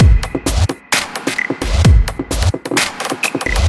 嘎吱嘎吱的鼓声，来自《我只想给你配音》。
描述：这里是铙钹和低音鼓的组合。
Tag: 140 bpm Dubstep Loops Drum Loops 2.31 MB wav Key : Unknown